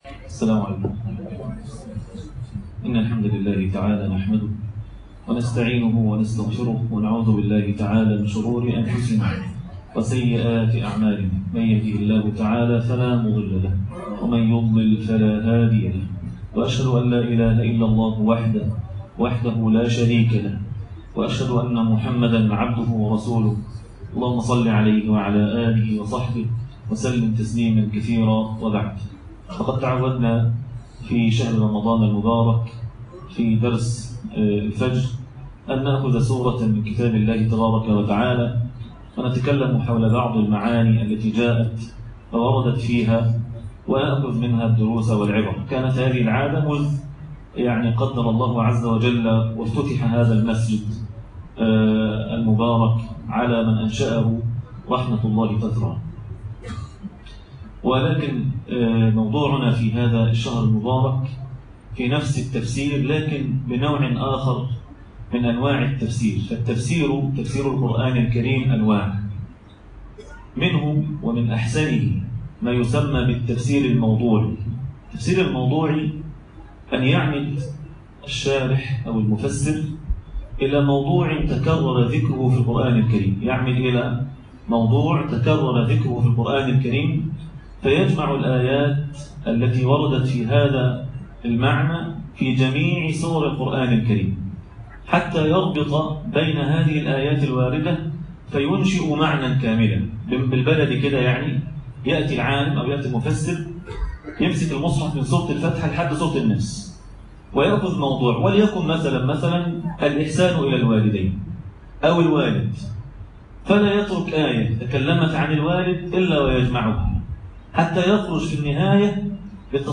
عنوان المادة بنو إسرائيل (القصة الكاملة) -درس الفجر - 1 رمضان 1438هـ تاريخ التحميل الأثنين 29 سبتمبر 2025 مـ حجم المادة 21.15 ميجا بايت عدد الزيارات 45 زيارة عدد مرات الحفظ 30 مرة إستماع المادة حفظ المادة اضف تعليقك أرسل لصديق